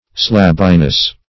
Slabbiness \Slab"bi*ness\, n. Quality of being slabby.